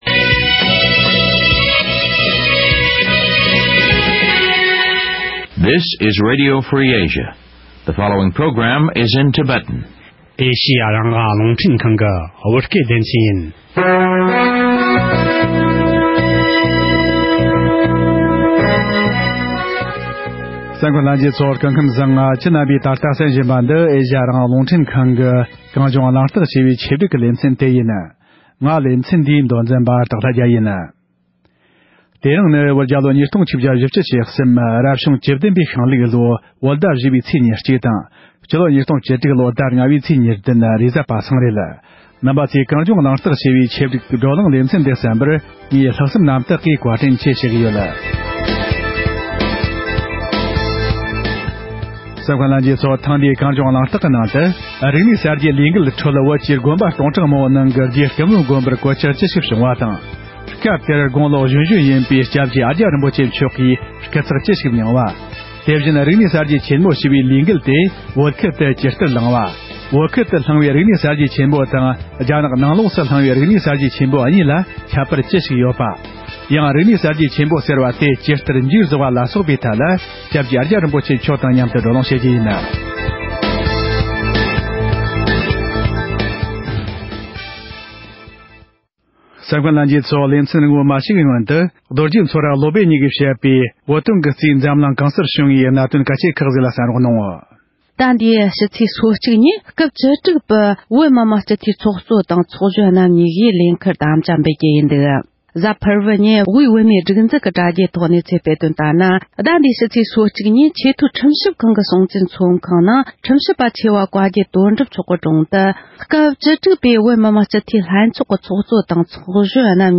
ཨ་ཀྱཱ་རིན་པོ་ཆེ་མཆོག་དང་ལྷན་དུ་རིག་གསར་སྐོར་བགྲོ་གླེང་།